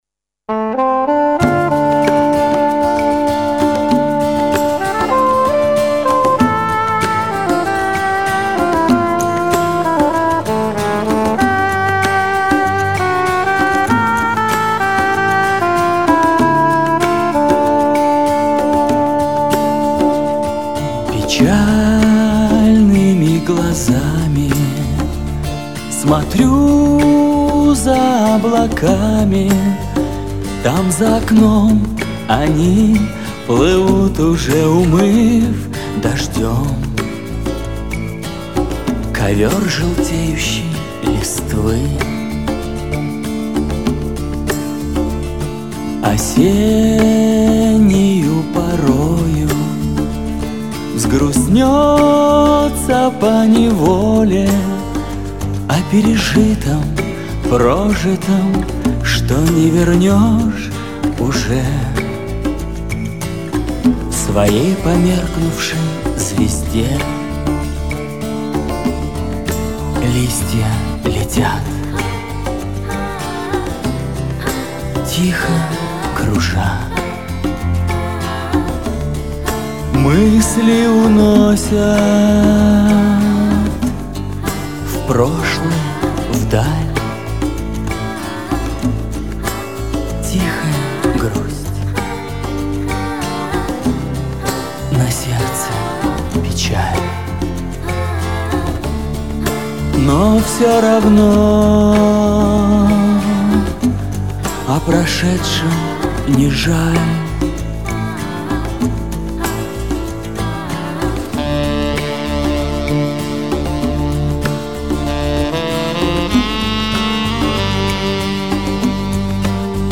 Голос красивый, но не оригинальный.